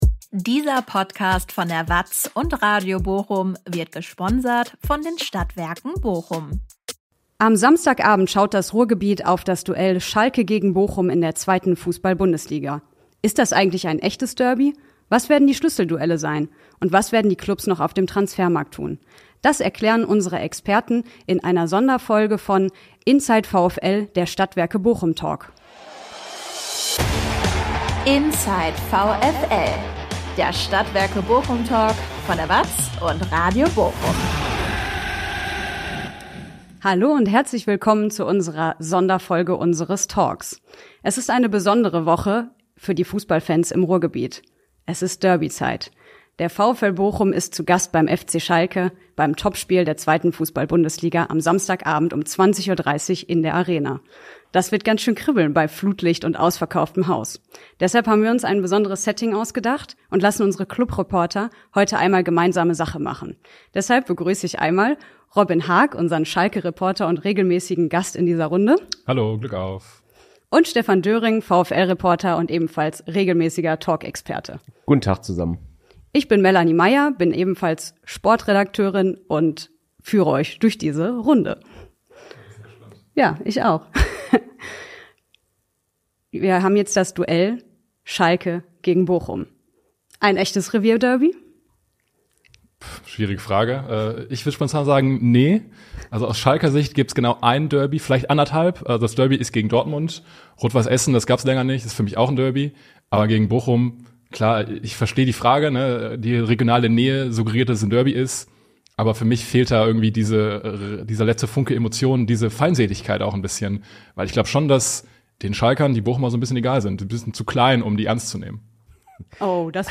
In einer Talk-Sonderfolge diskutieren ein Schalke- und ein VfL-Reporter über das anstehende Spiel zwischen dem S04 und Bochum darüber, ob es ein echtes Derby ist und wer zum Helden werden könnte.